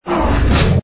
engine_li_freighter_start.wav